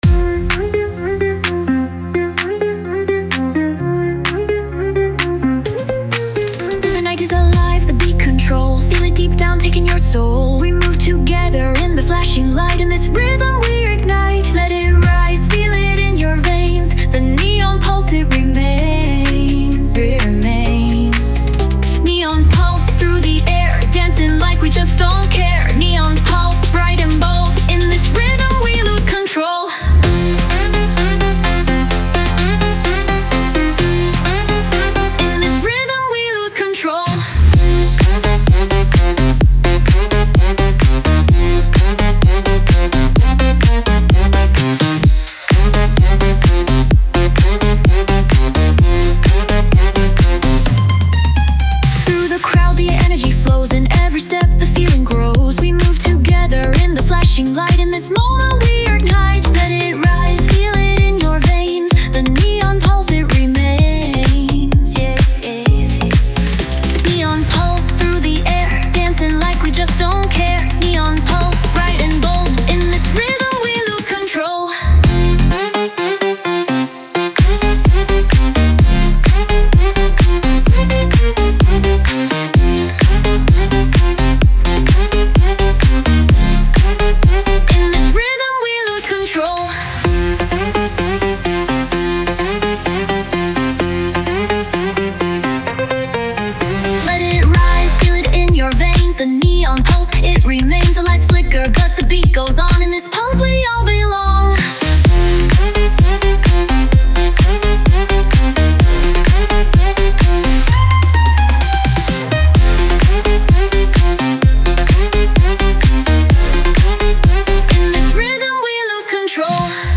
Tech House | Electro Pop | Synth Bass | Vocal-Driven Dance